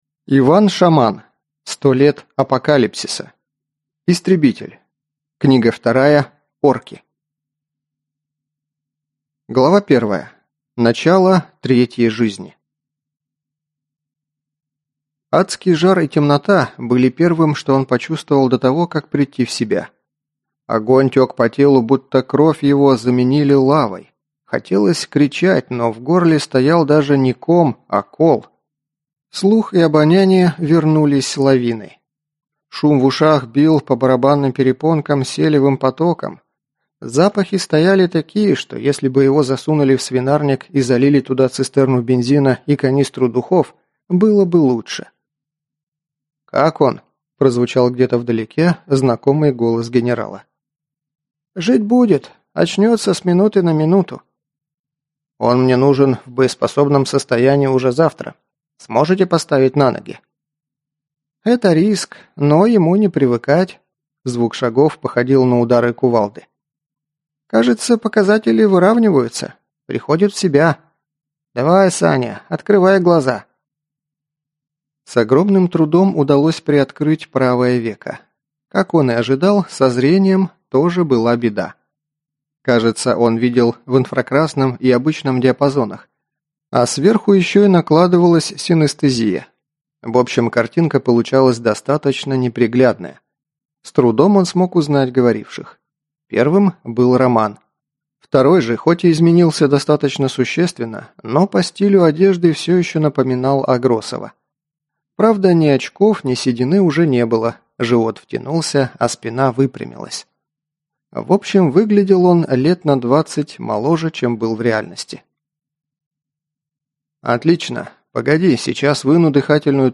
Аудиокнига Истребитель 2: Орки | Библиотека аудиокниг